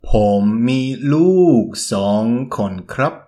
∨ pomm – mii ∧ luug ∨ song – konn / krabb